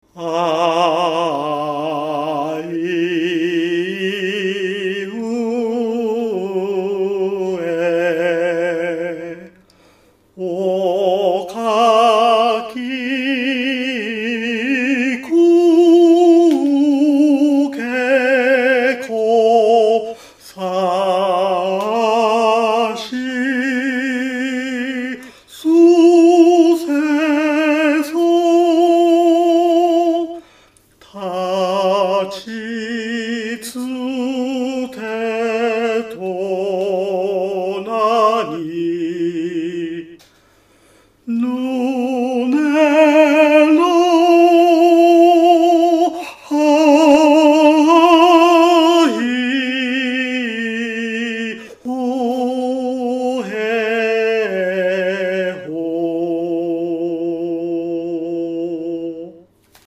残念ながら分担唱歌の仲間を集めることができませんでしたので、今回は僕の独唱でお届けします。
歌いおさめの「ふ～へ～ほ～」には荘厳ささえ感じます。
麗しのテナーにやられました。